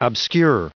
added pronounciation and merriam webster audio
535_obscure.ogg